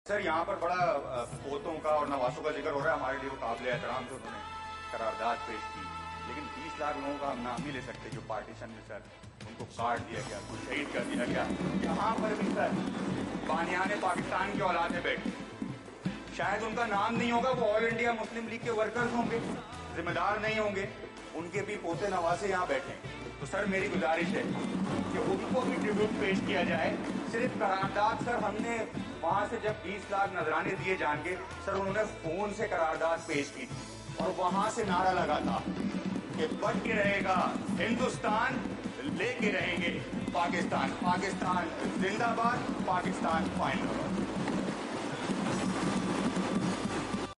حق پرست رکن سندھ اسمبلی انجینئر سید عثمان کا 78ویں یوم آزای کے موقع پر بانیان پاکستان کو سندھ کے ایوان میں خراج عقیدت!!!